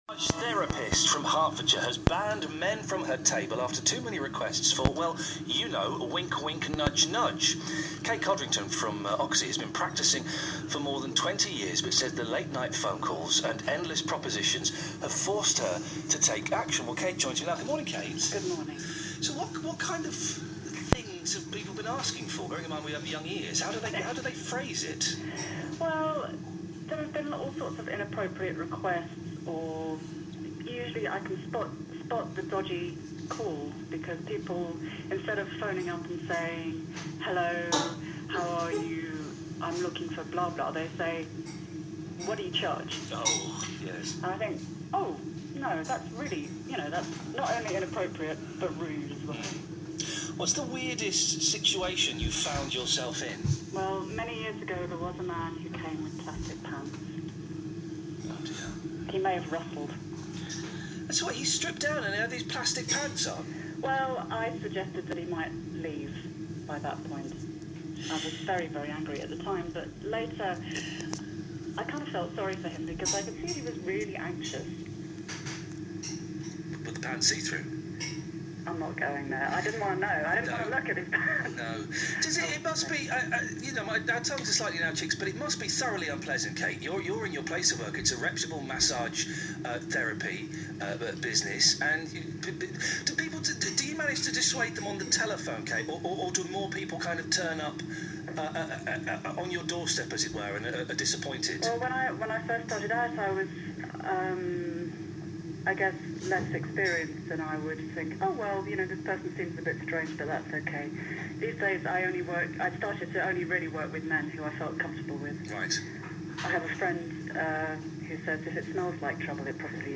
Chatting on Radio Three Counties about why I have stopped massaging men to concentrate on working with women's health and pregnancy.